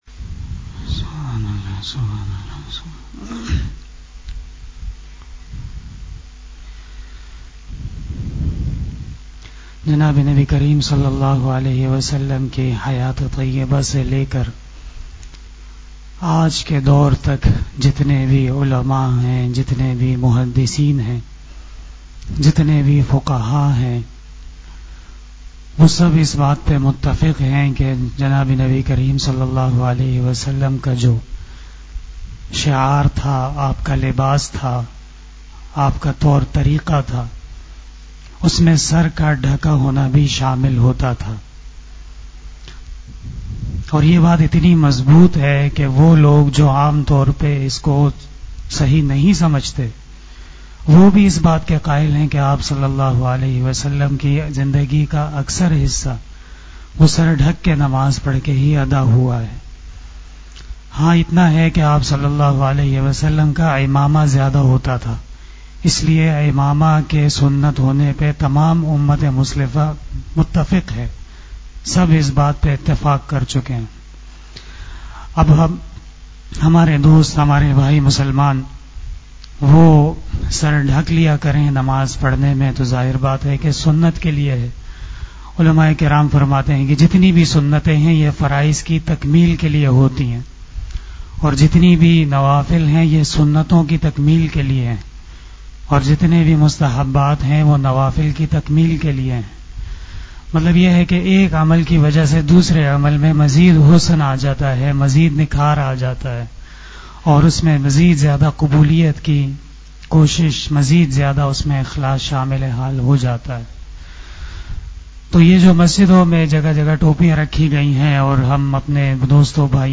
After magrib Namaz Bayan